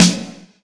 dssnare.wav